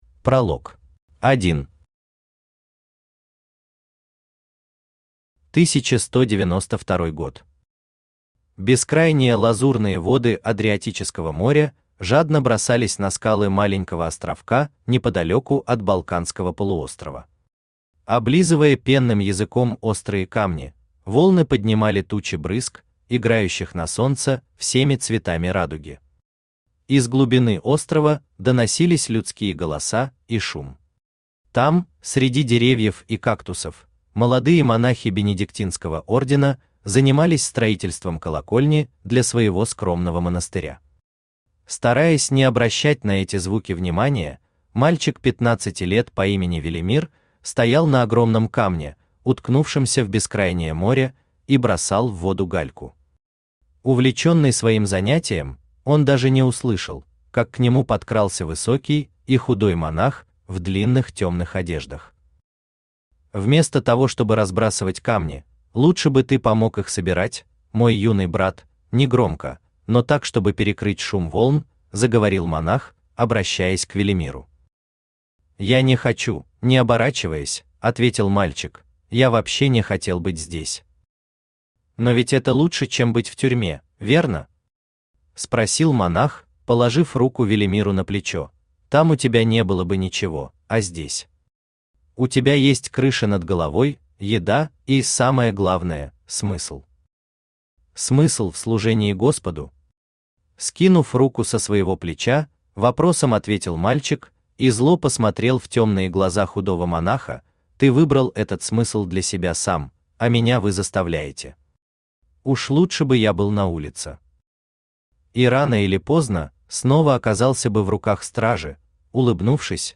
Aудиокнига Запертая комната Автор Илья Шевцов Читает аудиокнигу Авточтец ЛитРес.